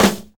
FLAM SNR.wav